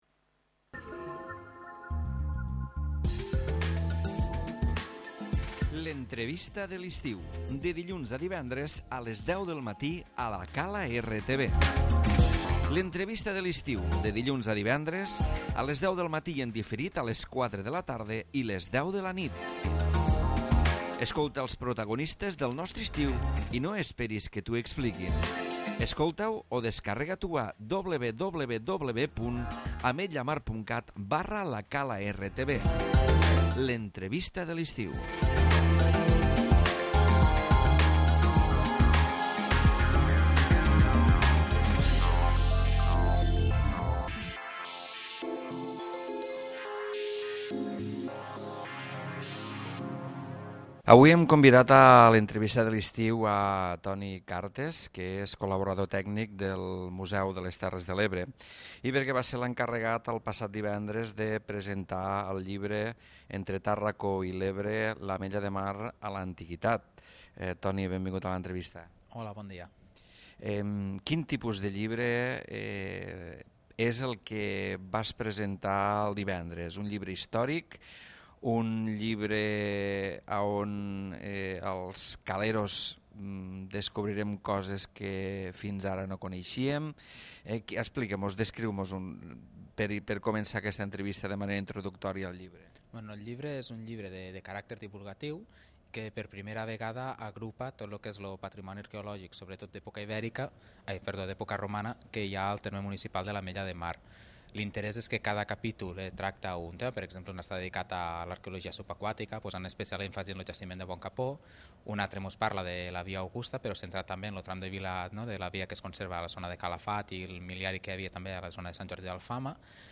L'Entrevista de l'Estiu